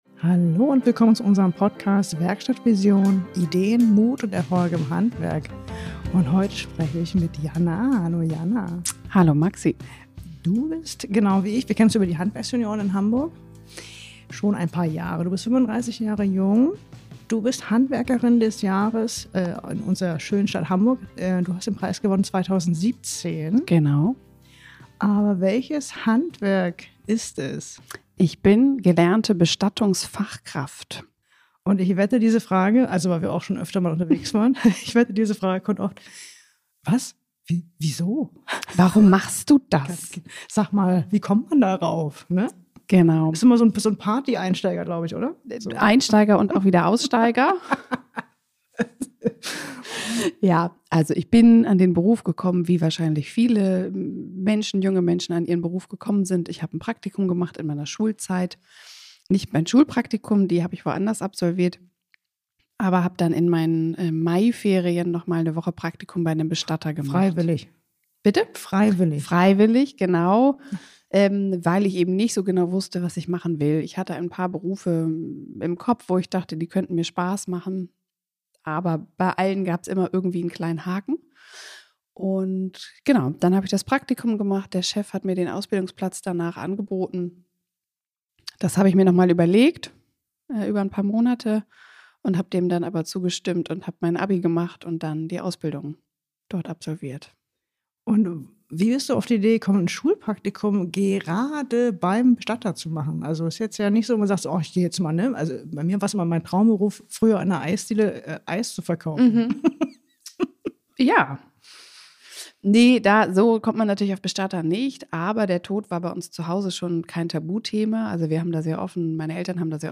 In jeder Folge hörst du inspirierende Geschichten und motivierende Interviews mit Handwerkern, die ihren ganz eigenen Weg gegangen sind – sei es in die Selbstständigkeit oder zu einzigartigen Erfolgen.